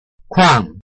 臺灣客語拼音學習網-客語聽讀拼-饒平腔-鼻尾韻
拼音查詢：【饒平腔】kuang ~請點選不同聲調拼音聽聽看!(例字漢字部分屬參考性質)